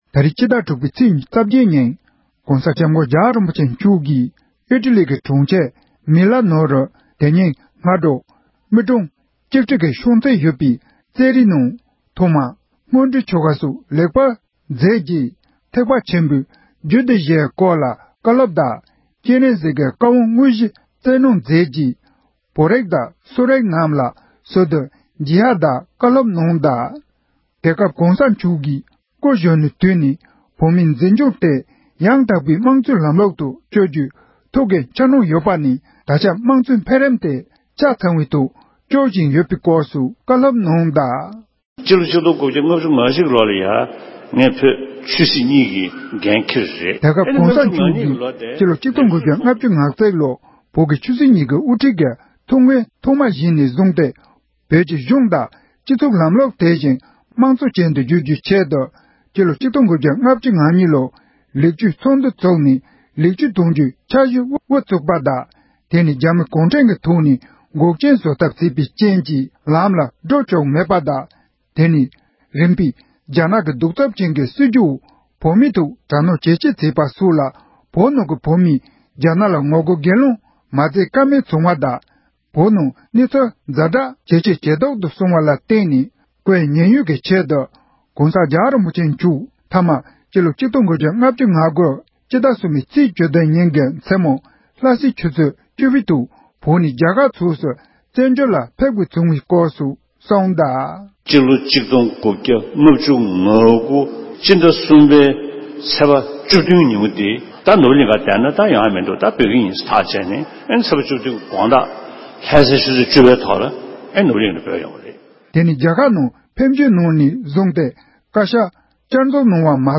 སྒྲ་ལྡན་གསར་འགྱུར། སྒྲ་ཕབ་ལེན།
༄༅༎དེ་རིང་གི་དམིགས་བསལ་གསར་འགྱུར་ནང་། ཕྱི་ལོ་ ༢༠༡༢ ཟླ་ ༦ ཚེས་ ༢༨ ཉིན། ༸གོང་ས་༸སྐྱབས་མགོན་ཆེན་པོ་མཆོག་གིས་ཨི་ཊ་ལིའི་གྲོང་ཁྱེར་མི་ལ་ནོའི་ནང་དུ་བོད་རིགས་དང་སོག་རིགས་རྣམས་ལ་མཇལ་ཁ་དང་བཀའ་སློབ་བསྩལ་གནང་ཡོད་པའི་སྐོར།